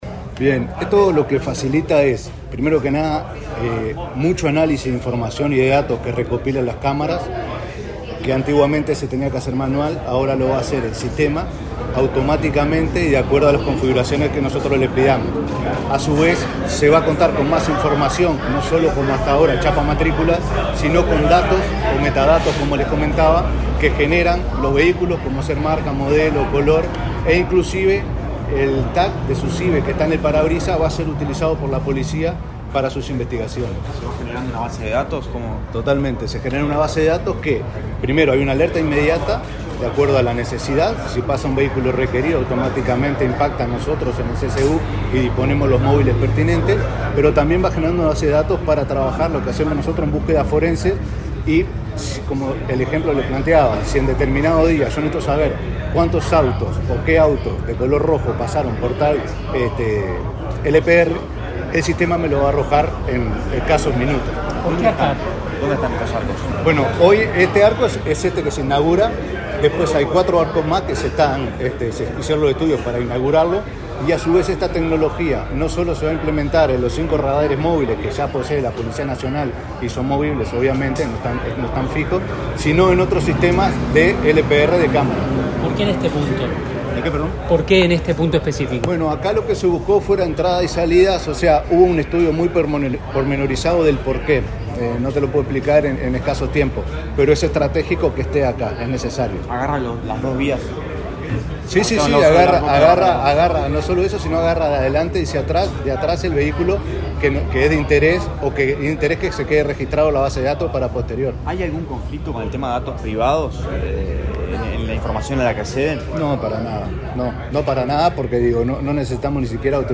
Declaraciones del director del Centro de Comando Unificado del Ministerio del Interior, Gabriel Lima
Declaraciones del director del Centro de Comando Unificado del Ministerio del Interior, Gabriel Lima 25/10/2024 Compartir Facebook X Copiar enlace WhatsApp LinkedIn Este viernes 25 en Montevideo, el Ministerio del Interior presentó el nuevo pórtico de matrículas y el software de analítica que se aplica en esta operativa. El director del Centro de Comando Unificado, Gabriel Lima, explicó a la prensa el alcance de esta medida.